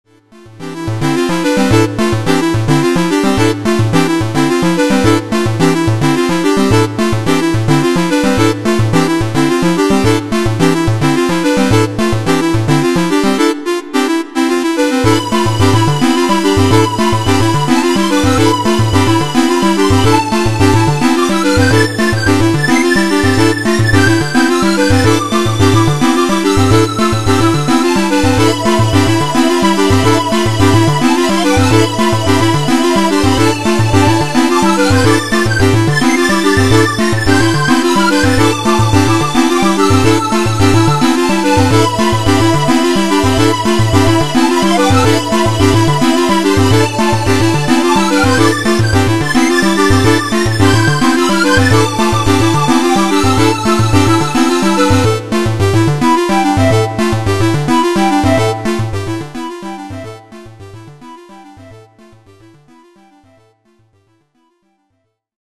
80年代エレクトリックミュージックを意識し、テクノポップの実践を試みたオリジナル曲集。
DEMO 785055 bytes / 01:05 / bpm108
MPEG 1.0 layer 3 / 96kbit / stereo